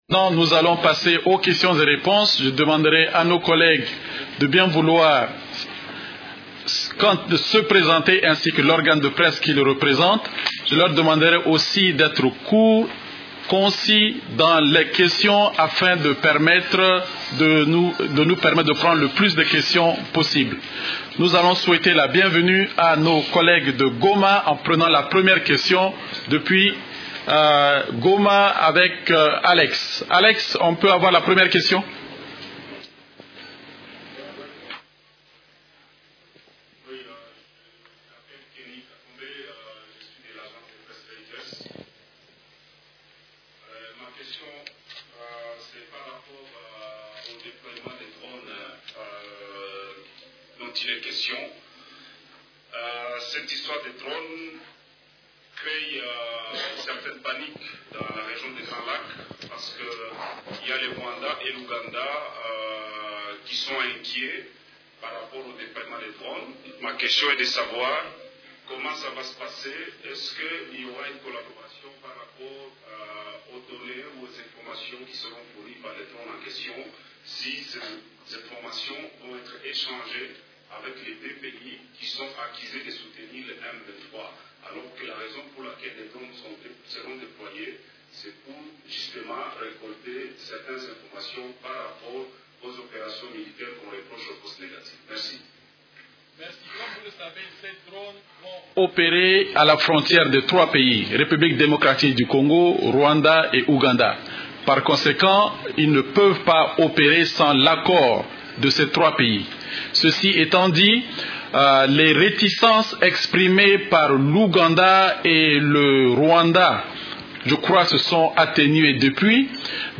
Conférence du 23 janvier 2013
La conférence hebdomadaire des Nations unies du mercredi 23 janvier a porté sur les sujets suivants:
Le verbatim de cette conférence de presse.